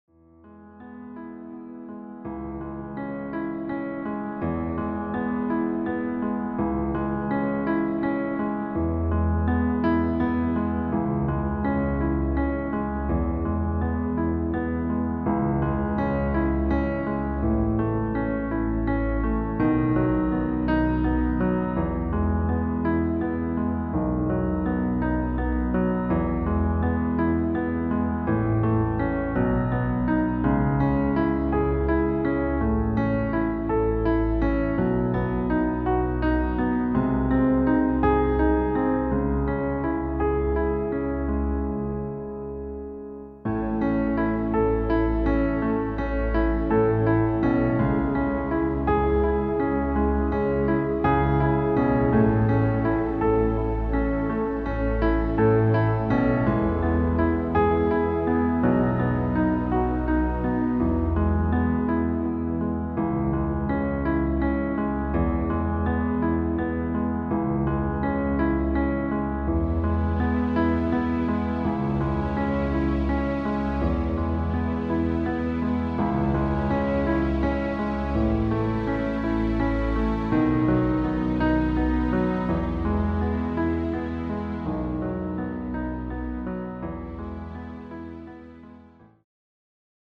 • Tonart: Eb Dur, E-Dur, F-Dur, G-Dur
• Art: Klavierversion mit Streicher
• Das Instrumental beinhaltet keine Leadstimme
Lediglich die Demos sind mit einem Fade-In/Out versehen.
Klavier / Streicher